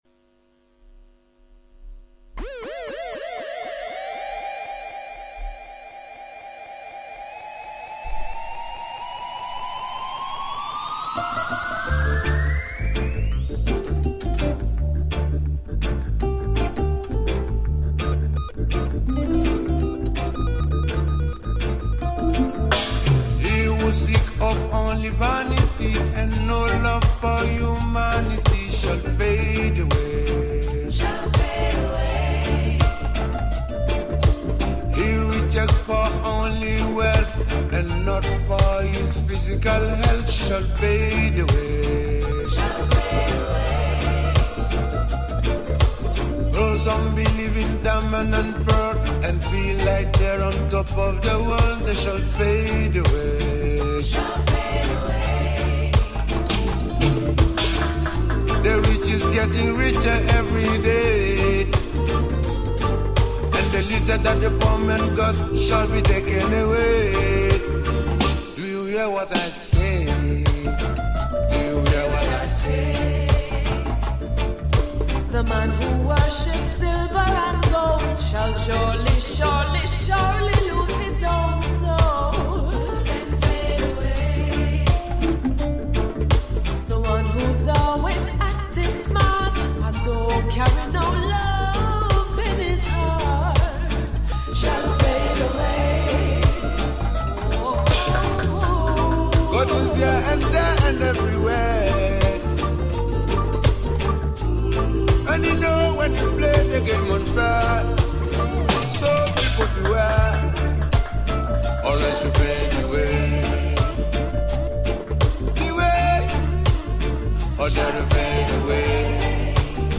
Strictly Vinyl Selection.